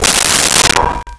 Metal Lid 04
Metal Lid 04.wav